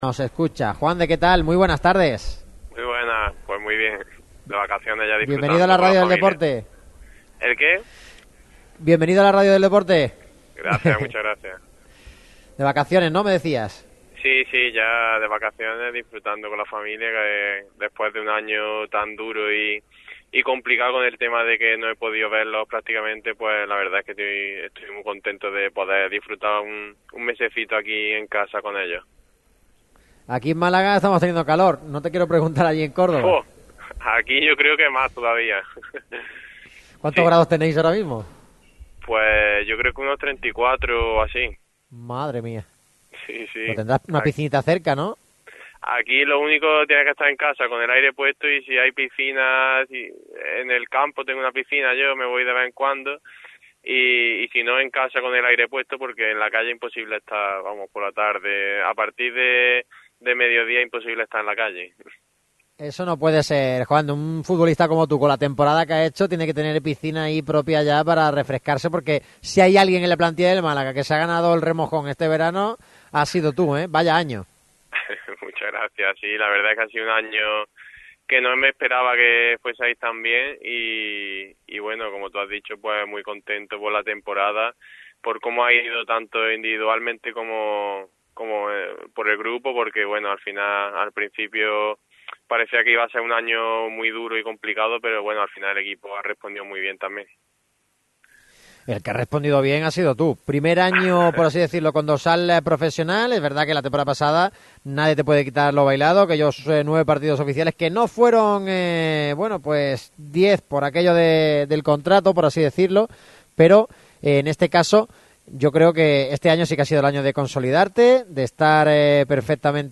La entrevista se iniciaba con un rápido análisis de la temporada 20/21.